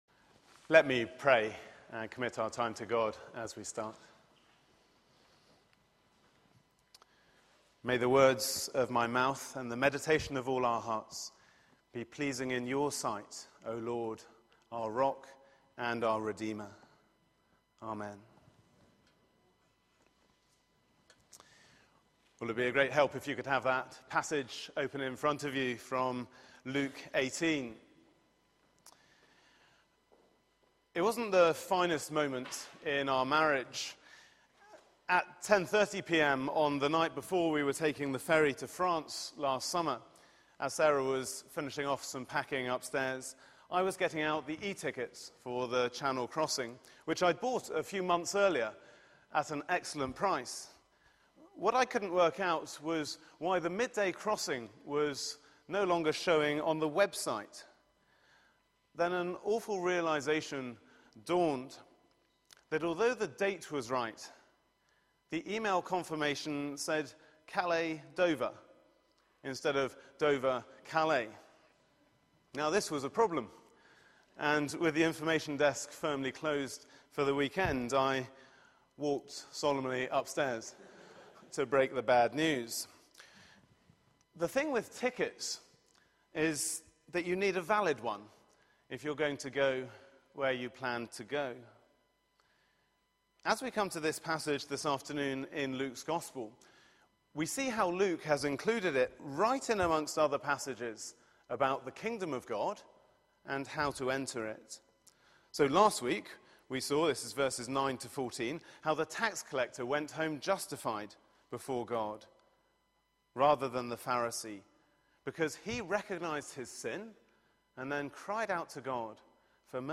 Media for 4pm Service on Sun 03rd Nov 2013 16:00 Speaker
Sermon